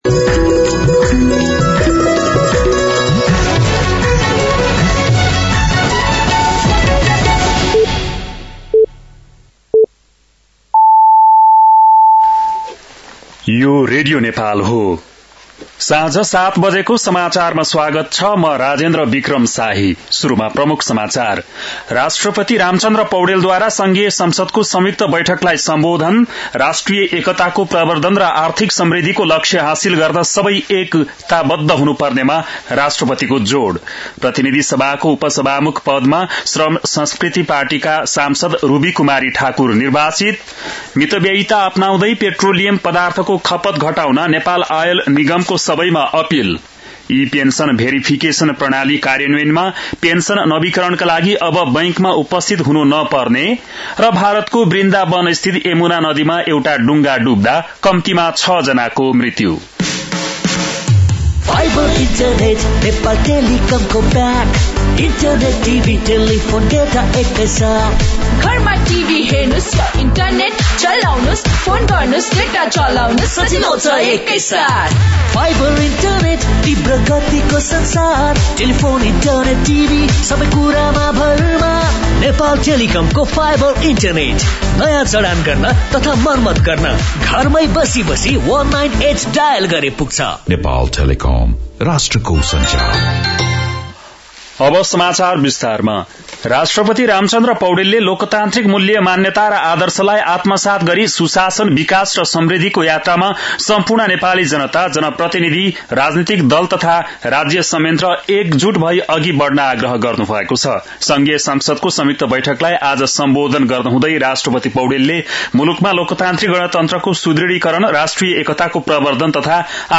बेलुकी ७ बजेको नेपाली समाचार : २७ चैत , २०८२